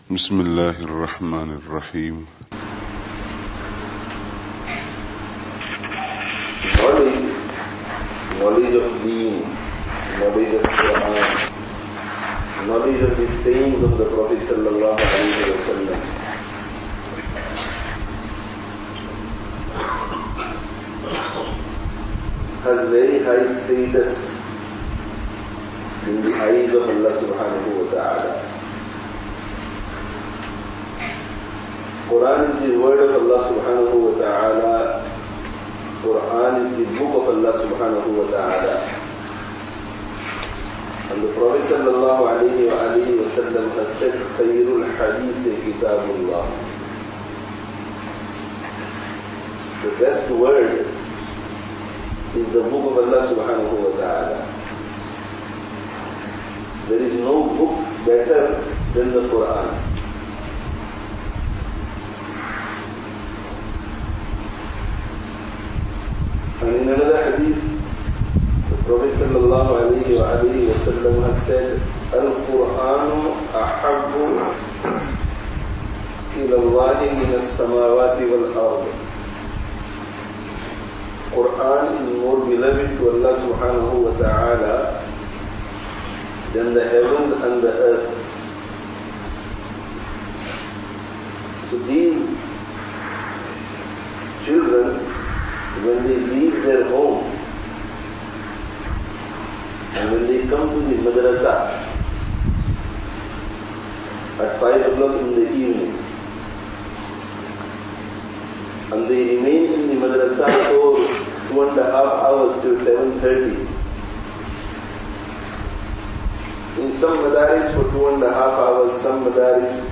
Seeking 'Ilm [Annual Presentation Jalsah] (Masjid An Noor, Leicester 13/08/09)